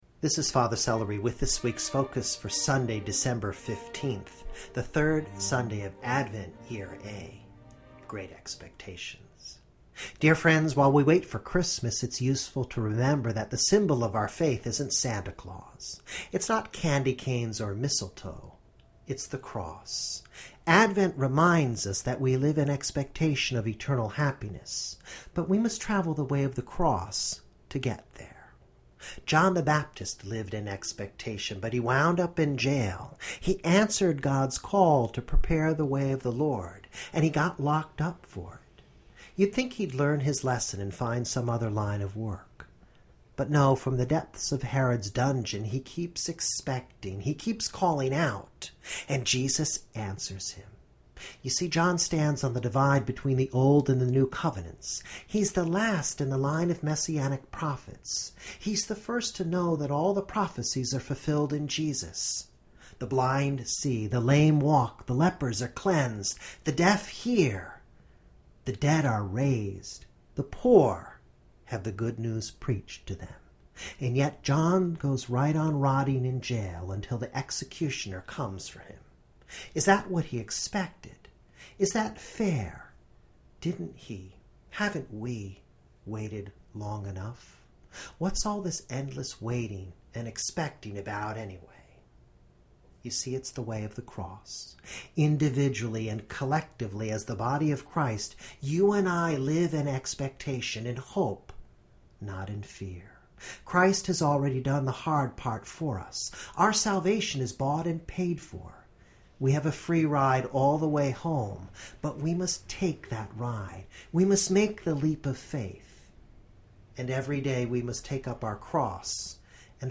Brief Audio reflection for Advent 3: Year A.